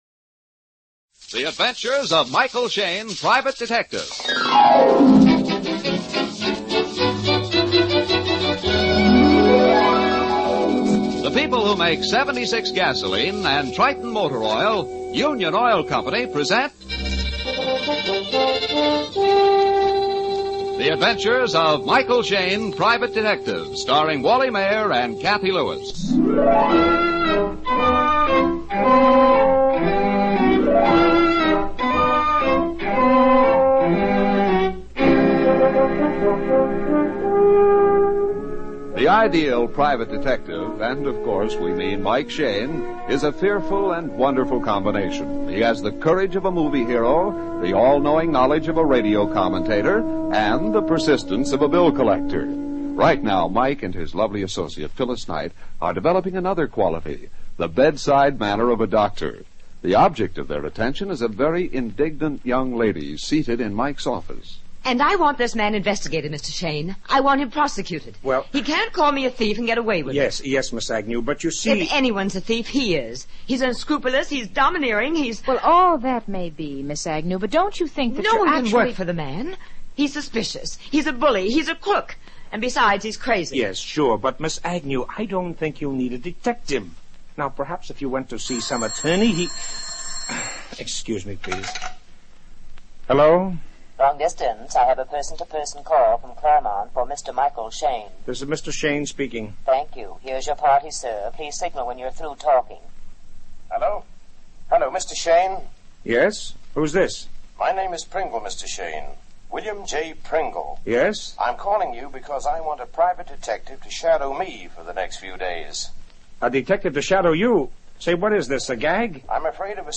Michael Shayne 450924 Shadow William P Pringle, Old Time Radio